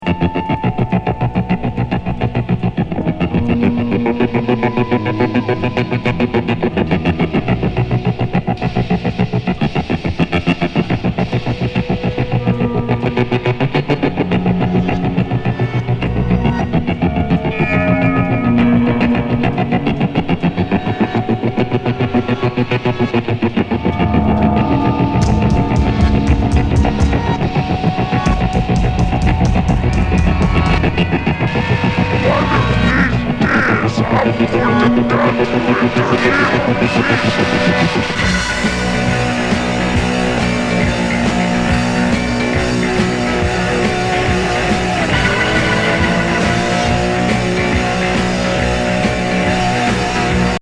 嵐吹き荒ぶプログレ大名曲。